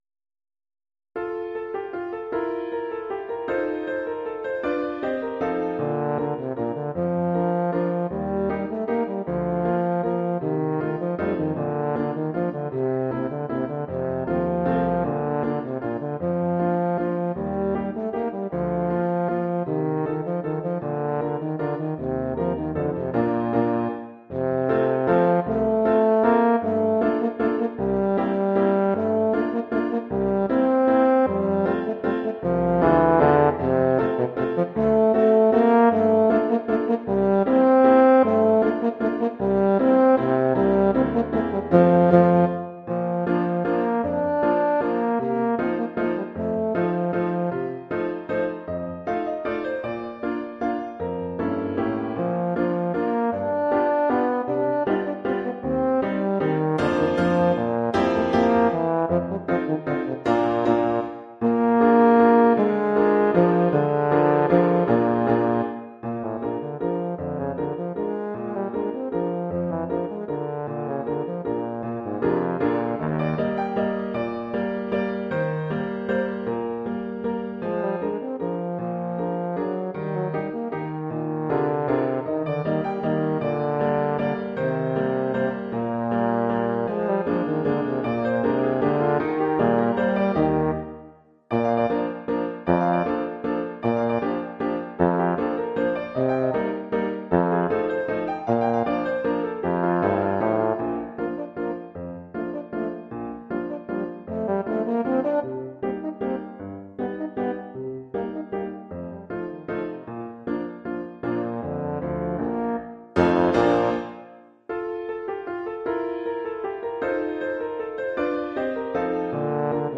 Oeuvre pour saxhorn basse et piano.
Oeuvre pour saxhorn basse / euphonium /
tuba et piano.
(instrument avec accompagnement de piano et piano seul).